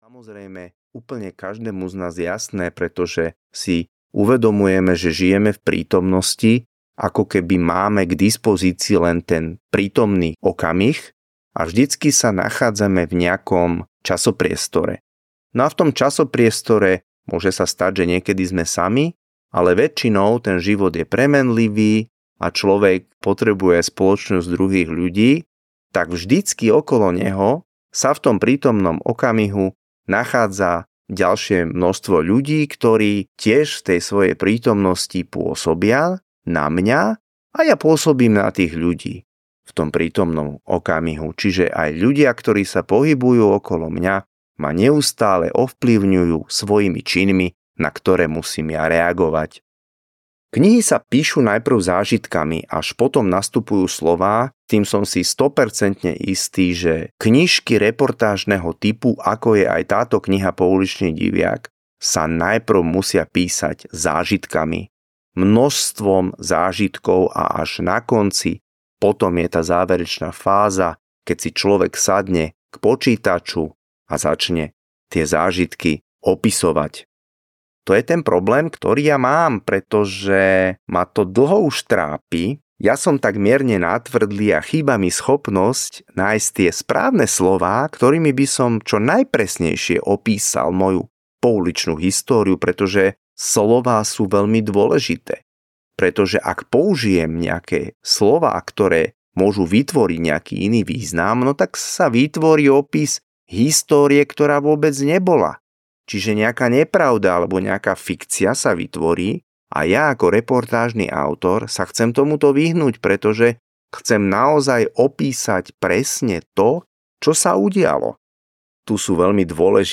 Ukázka z knihy
poulicny-diviak-audiokniha